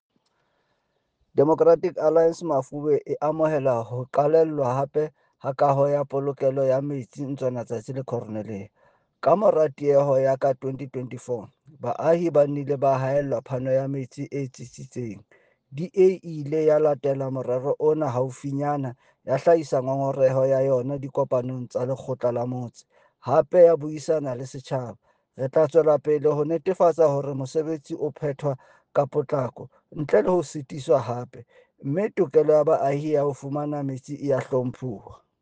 Issued by Cllr. Fako Tsotetsi – DA Councillor Mafube Municipality
Sesotho soundbites by Cllr Fako Tsotetsi.